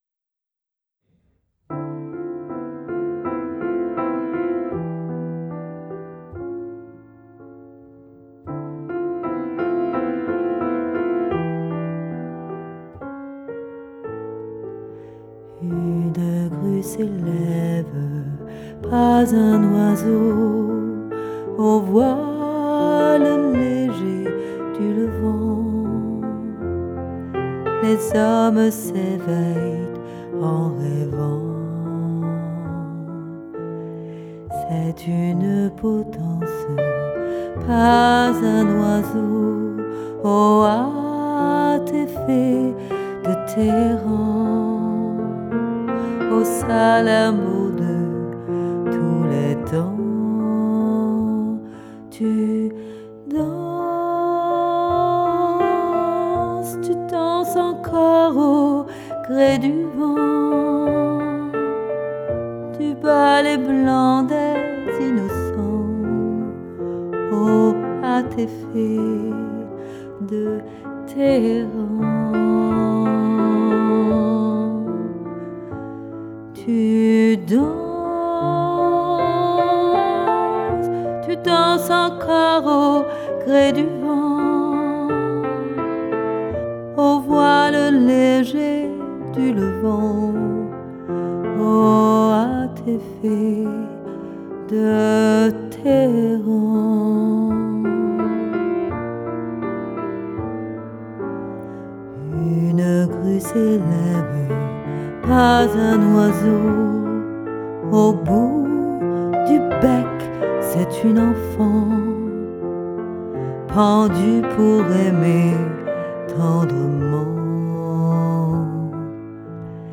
Piano
Les deux premières (maquette)